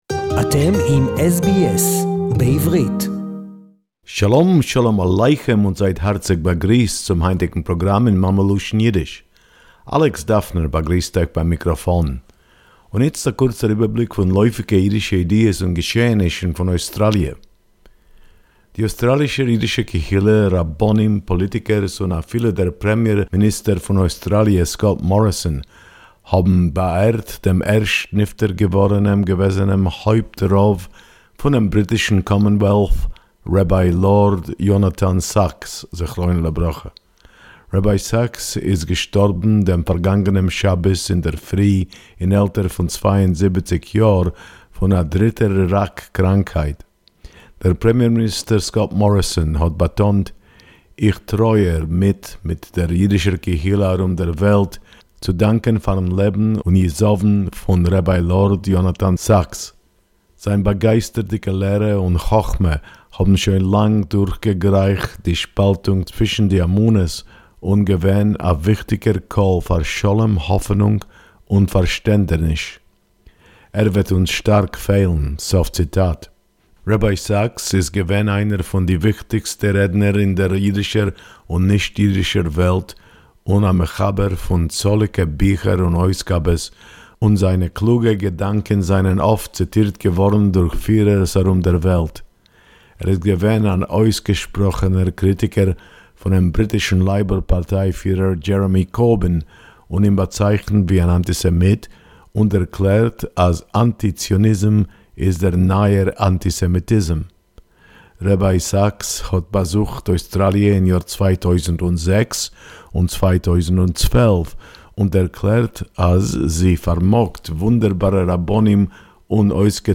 Tribute to Rabbi Lord Jonathan Sacks z”l, Yiddish report 15.11.2020